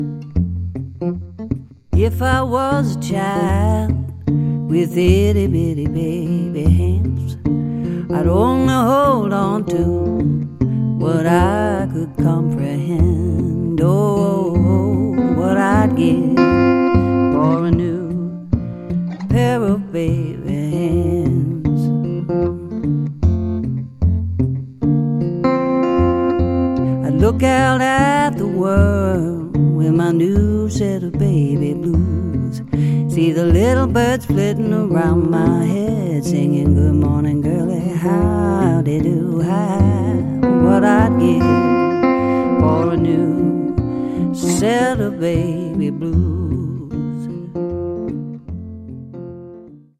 Drums
Vocals, Guitar